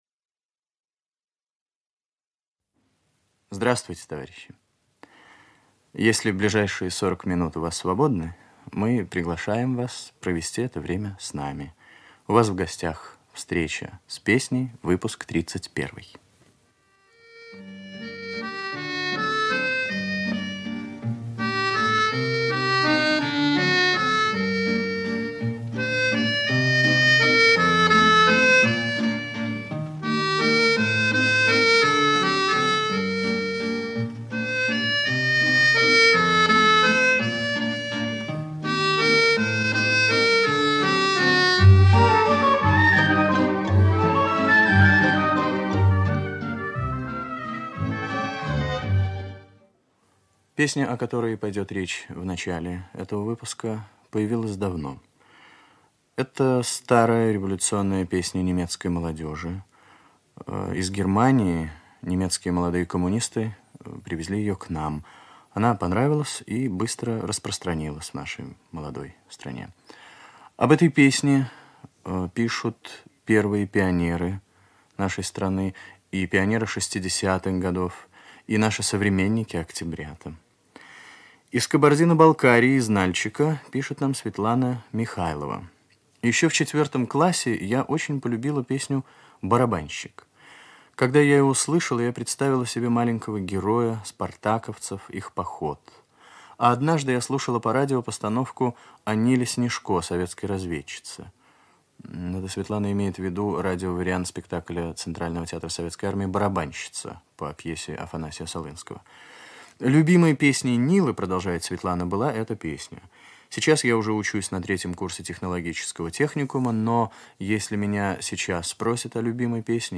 1. Музыкальная заставка к передаче.
Старинный русский романс